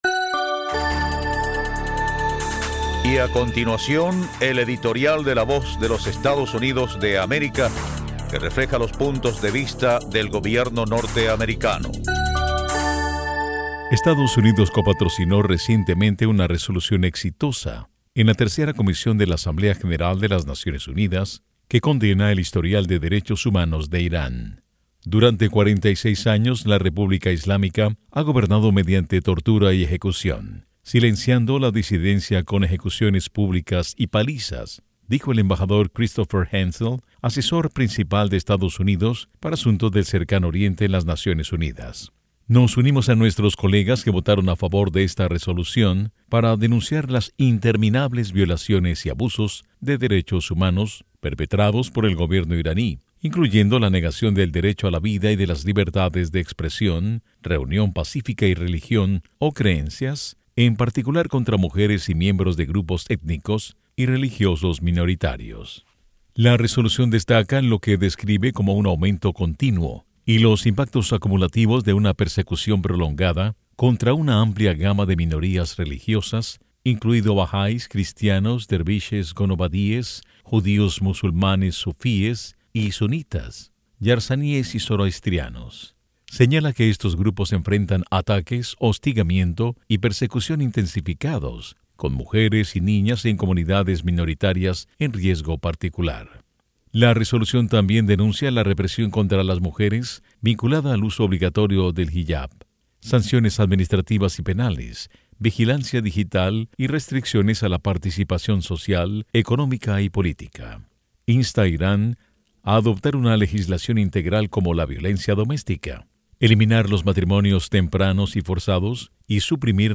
A continuación, un editorial que refleja las opiniones del Gobierno de los Estados Unidos: Estados Unidos copatrocinó recientemente una resolución exitosa en la Tercera Comisión de la Asamblea General de las Naciones Unidas que condena el historial de derechos humanos de Irán.